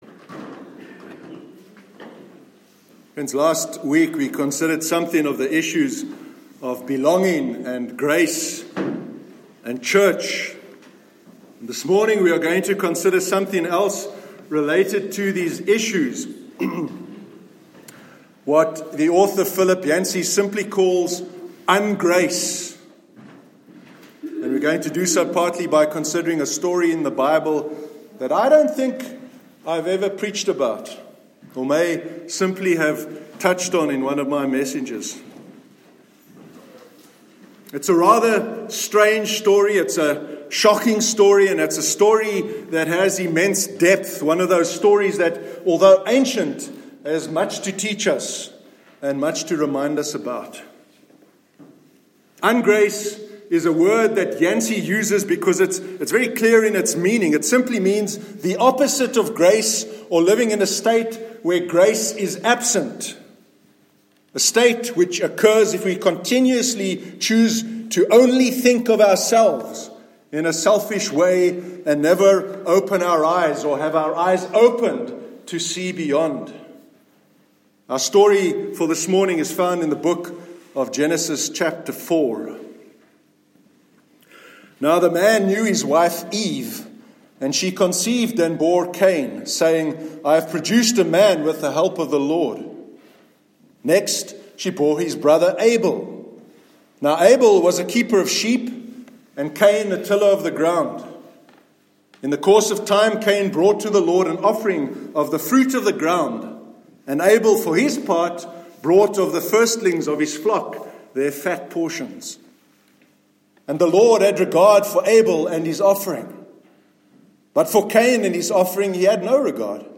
Sermon on “Ungrace”- 27th May 2018 – NEWHAVEN CHURCH
Sermon on “Ungrace”- 27th May 2018